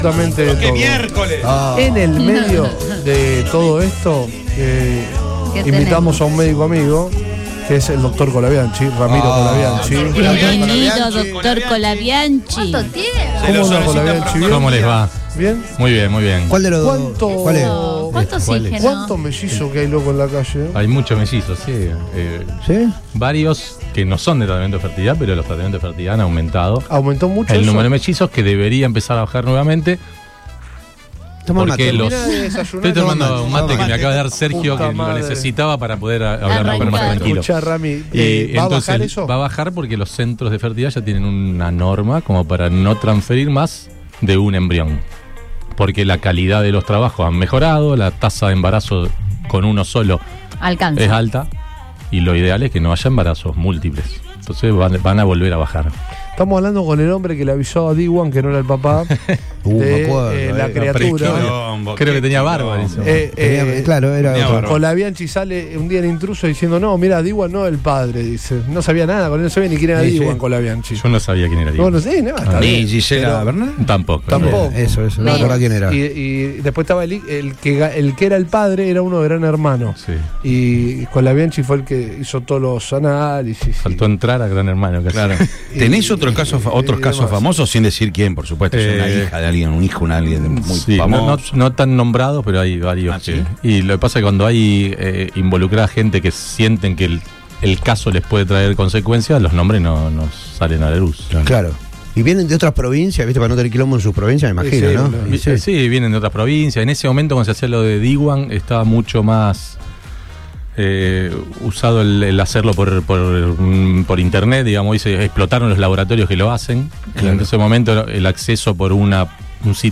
visitó los estudios de Todo Pasa en Radio Boing